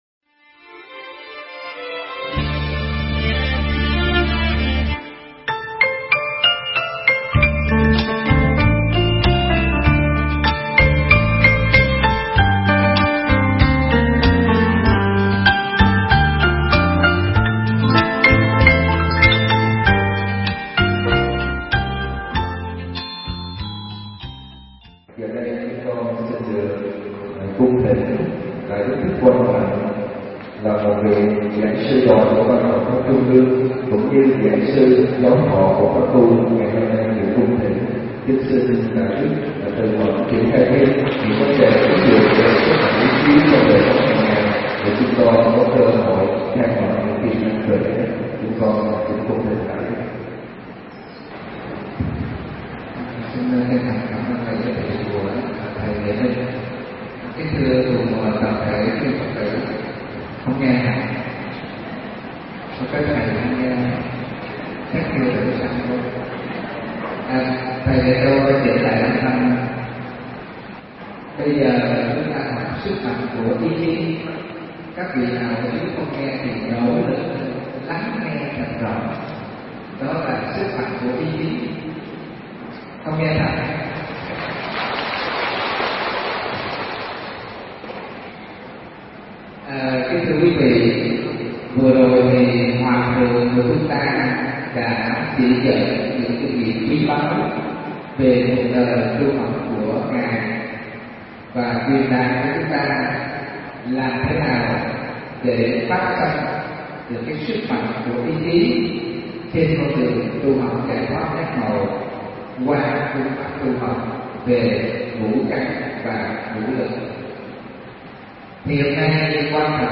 Nghe Mp3 thuyết pháp Sức Mạnh Của Ý Chí